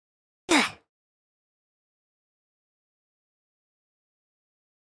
Roblox New Reset Character Sound Effect Free Download